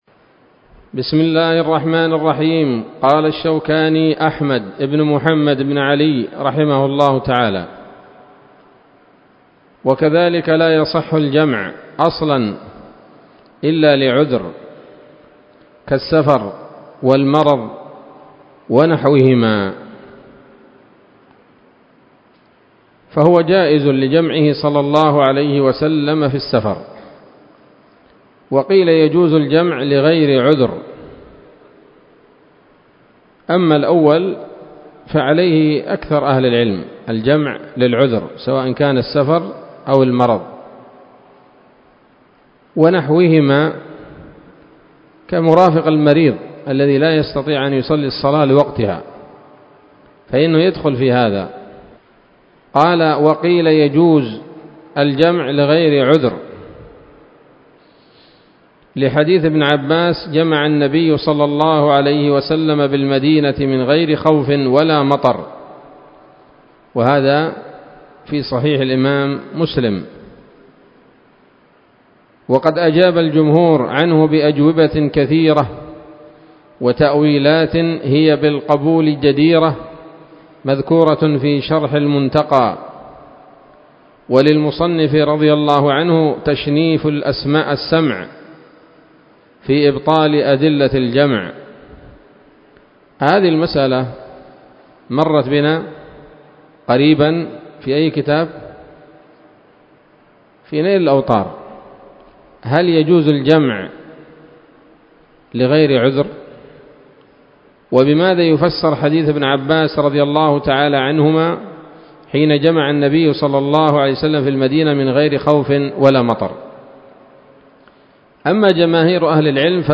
الدرس الرابع من كتاب الصلاة من السموط الذهبية الحاوية للدرر البهية